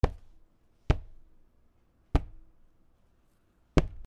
/ M｜他分類 / L01 ｜小道具 /
木の台を叩く